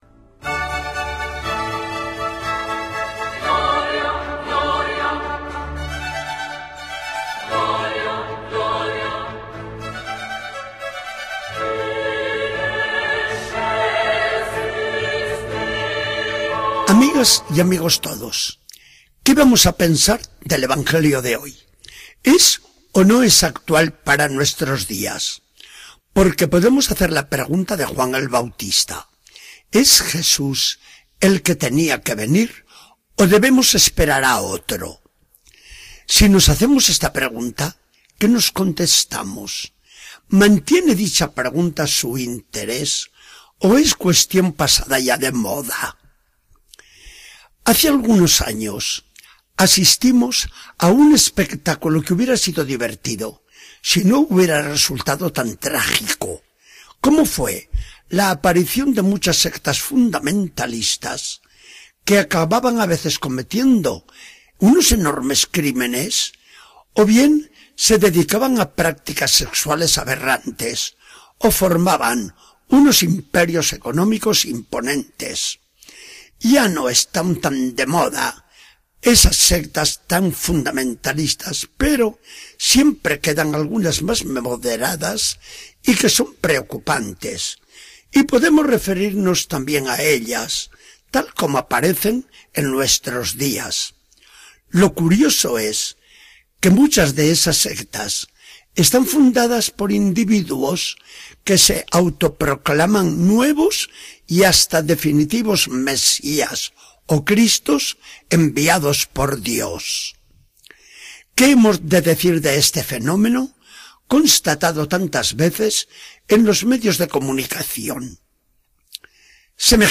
Charla del día 15 de diciembre de 2013. Del Evangelio según San Mateo.